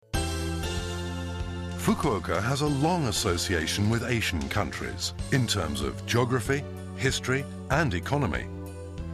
注：音声データは、DVD「DISCOVER Fukuoka City」の英語リスニング教材